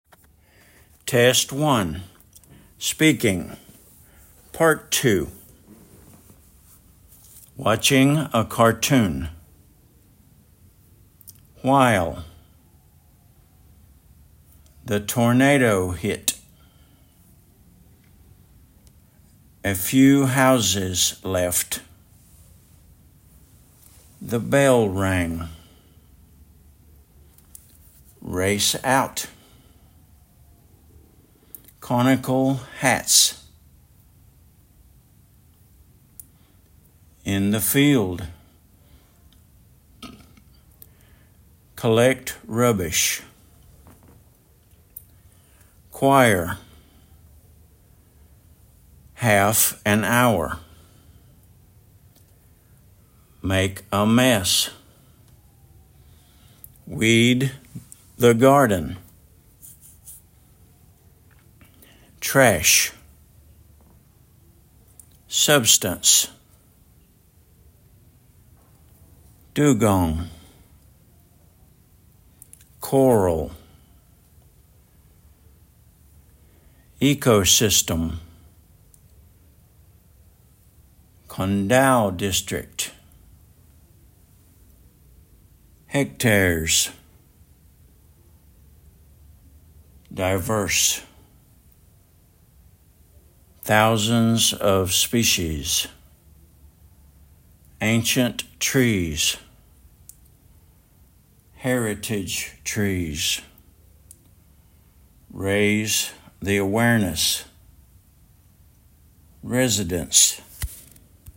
choir /kwaɪə/
dugong /ˈdjuːɡɒŋ/
ecosystem /ˈiːkəʊˌsɪstəm/
Con Dao district /kɒn daʊ ˈdɪstrɪkt/
hectares /ˈhɛktɛəz/